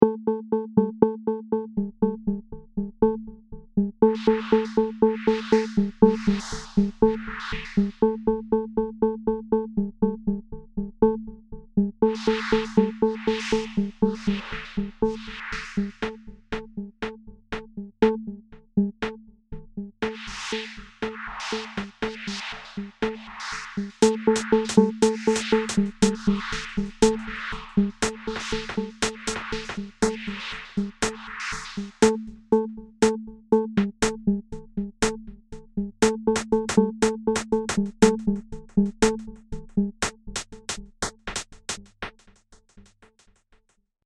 Bucle de electrónica antigua
Música electrónica
percusión
repetitivo
rítmico
sintetizador